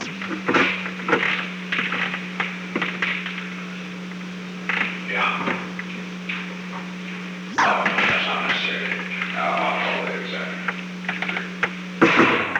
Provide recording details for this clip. The Oval Office taping system captured this recording, which is known as Conversation 498-006 of the White House Tapes. Location: Oval Office